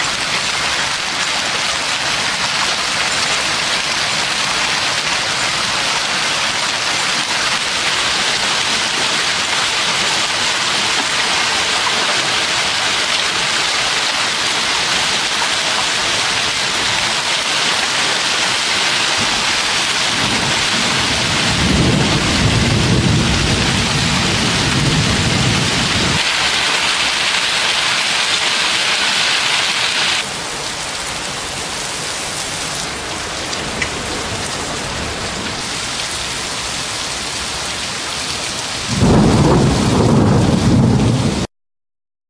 Hagelgewitter 15.06.2003